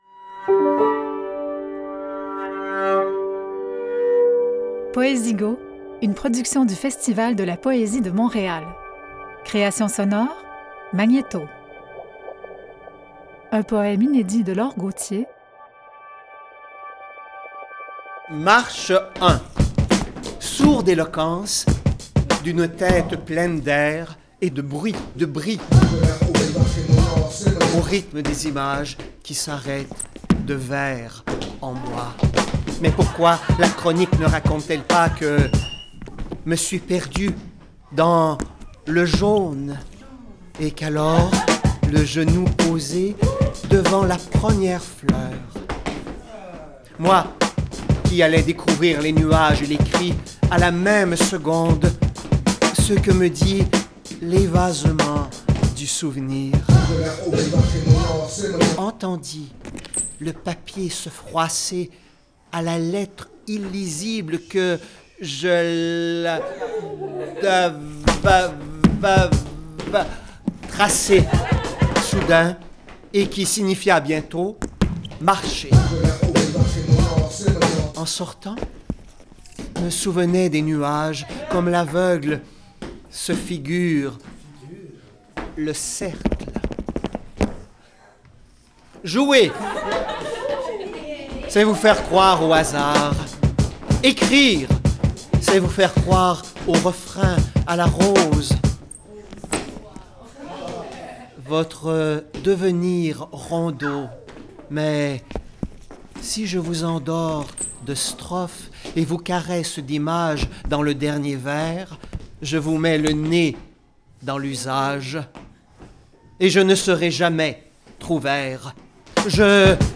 pour rendre la poésie à l’oralité.
Réalisation et création sonore : Magnéto